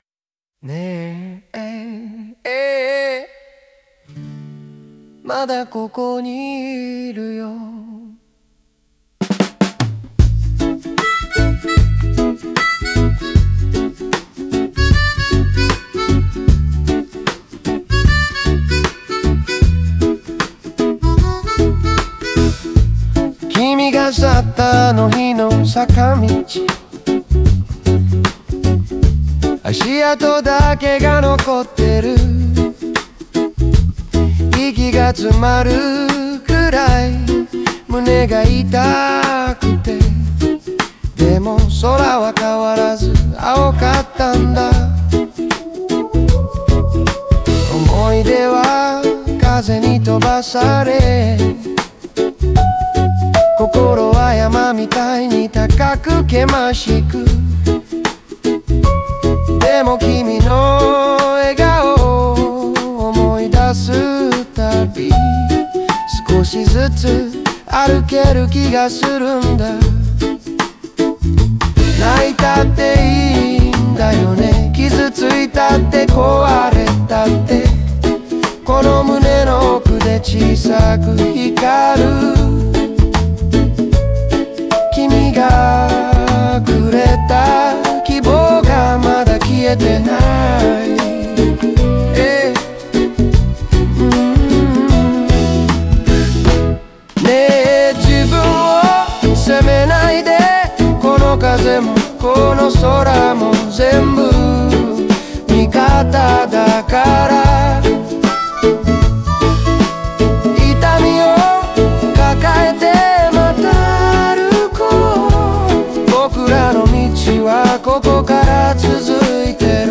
語りかける口語調で、痛みや涙を受け止めつつ励まし、優しさを込めることで、聞き手にも共感と希望を届ける構造になっています。